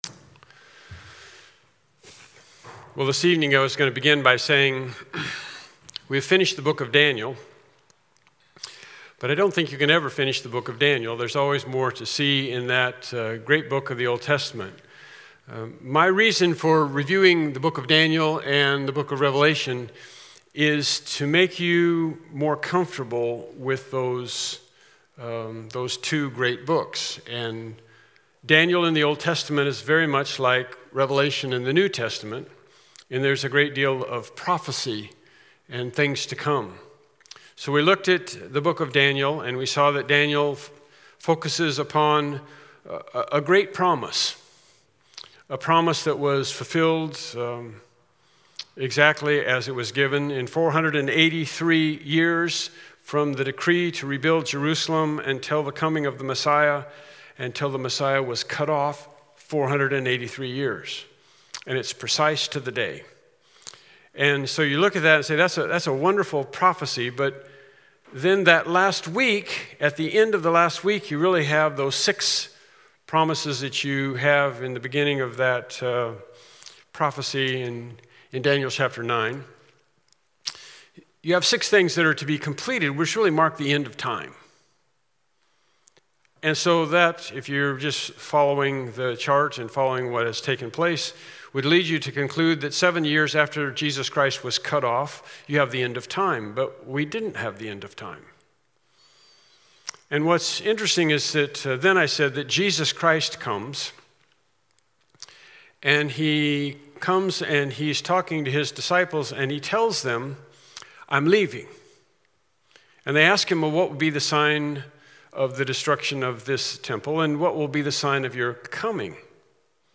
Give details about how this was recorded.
Revelation Service Type: Evening Worship Service « “The Rescue Mission” “All Hope in God” »